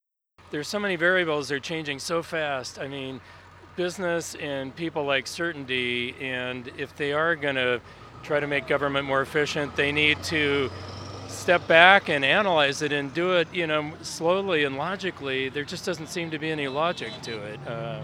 Nearly 200 people gathered for a “Hands Off!” protest Saturday in Madras, joining thousands gathered in other cities nationwide.